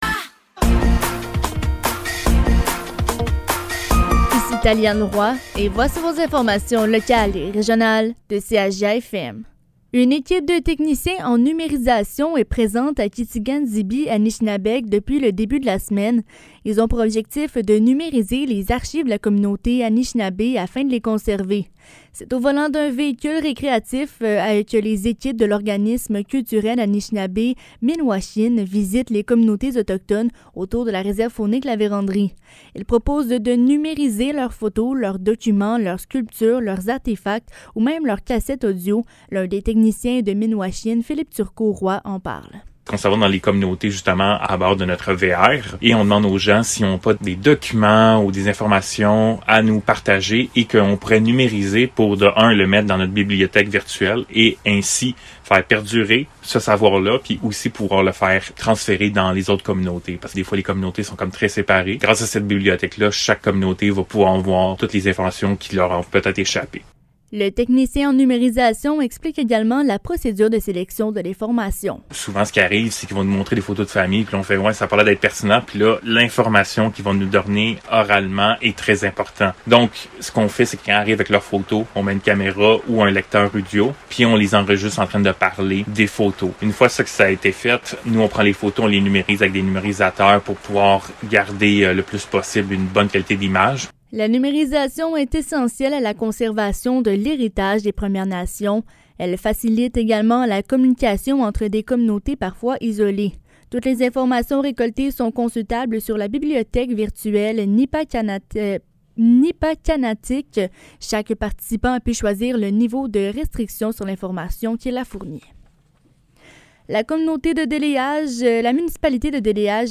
Nouvelles locales – 10 août 2023 – 15 h | CHGA